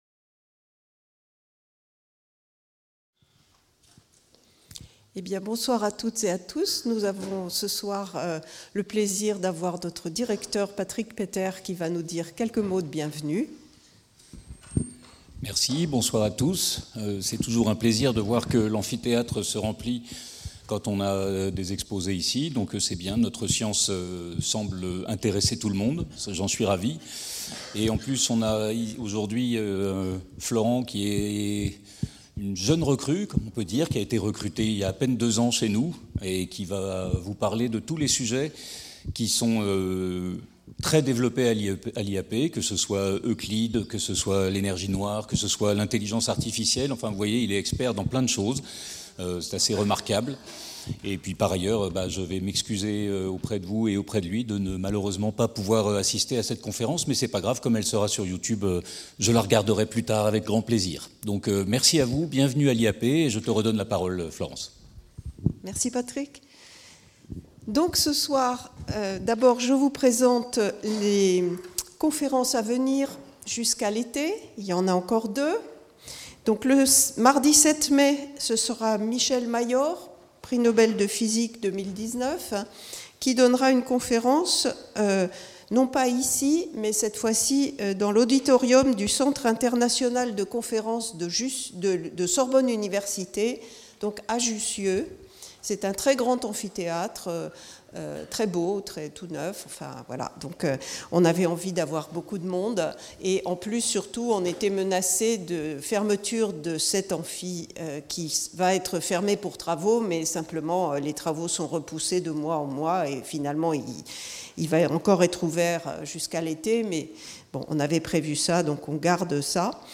Une des frontières majeures de la cosmologie contemporaine est d'élucider la nature de l'énergie noire, qui est responsable d'un phénomène mystérieux : l'accélération de l'expansion de l'Univers. Au cours de cette conférence, nous explorerons la physique exotique de l'énergie noire, les différents modèles possibles et leurs implications pour l'avenir de l'Univers.